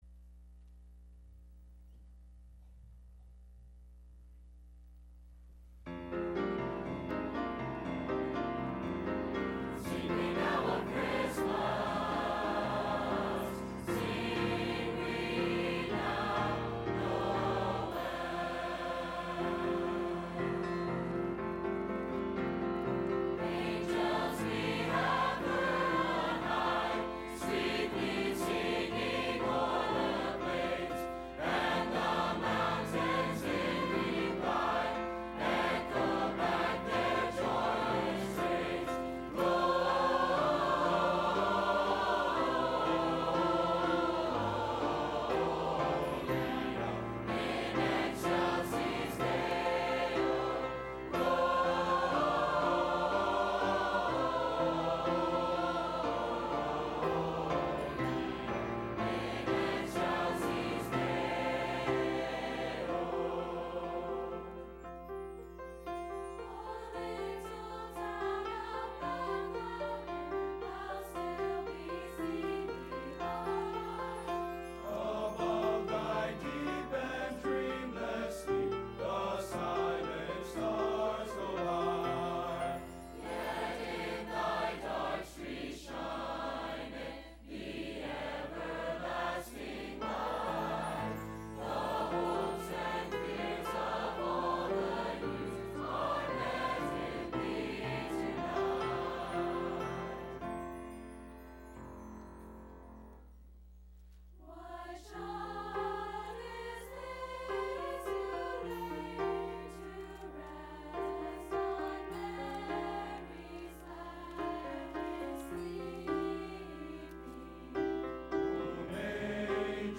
College Christmas Program – Landmark Baptist Church
Service Type: Wednesday